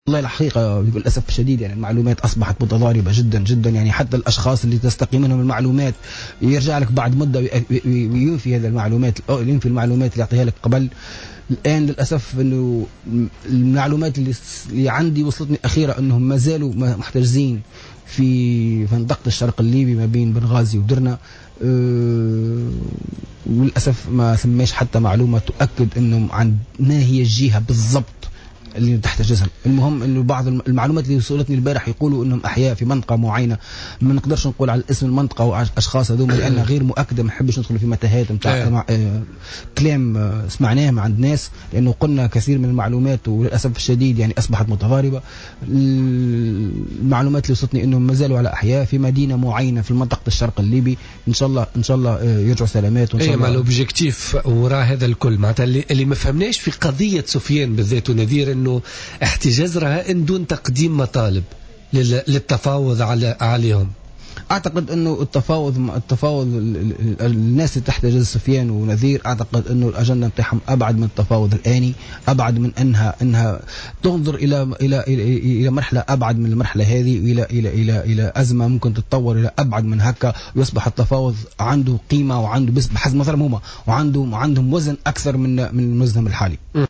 ضيف برنامج بوليتيكا